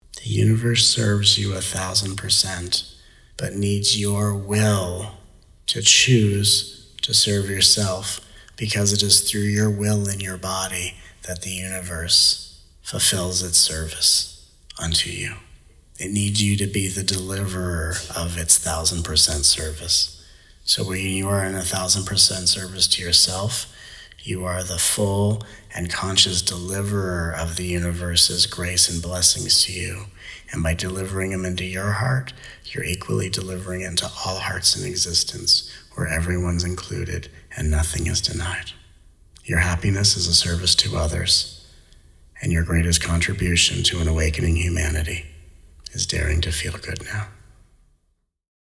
Recorded over the course of the March 2017 "Feel Good Now" 5-day Soul Convergence